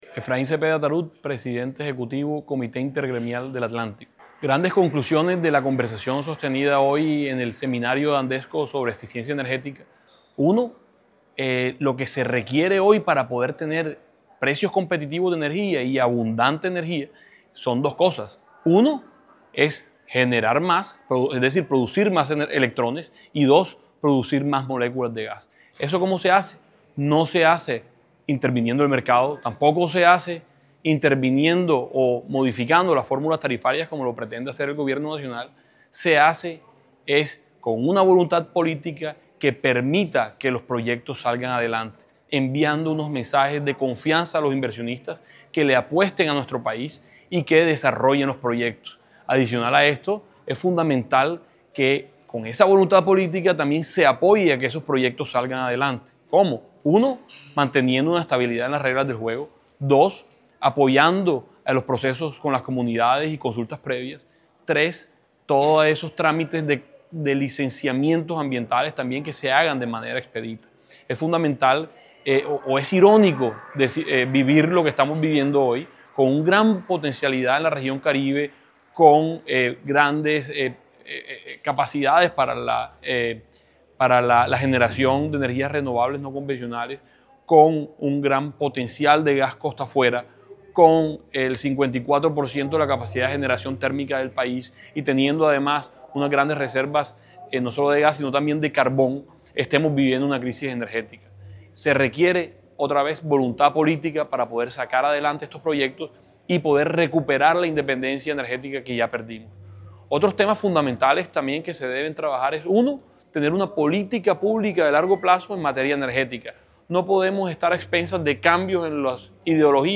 Audio-Efrain-Cepeda-presidente-del-Comite-Intergremial-del-Atlantico.mp3